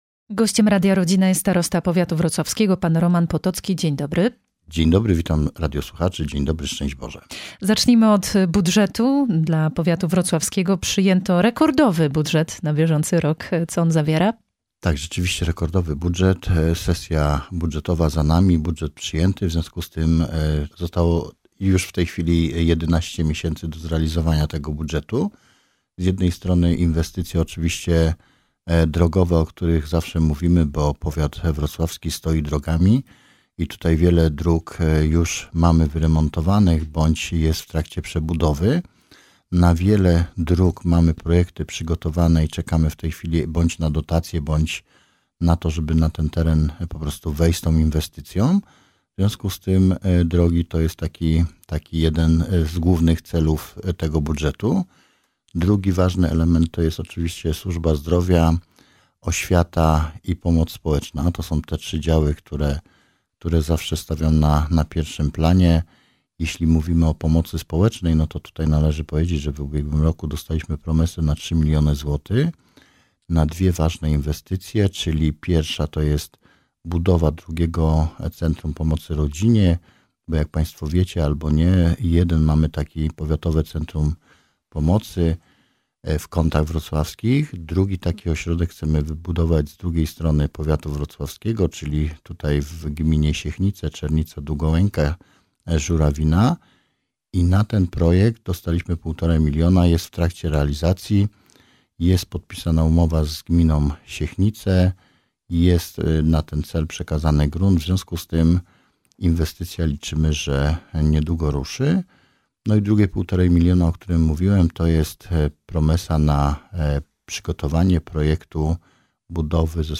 Naszym gościem jest Starosta Powiatu Wrocławskiego – Roman Potocki, z którym rozmawiamy o bieżących inwestycjach, działaniach edukacyjnych i profilaktyce w ramach polityki zdrowotnej.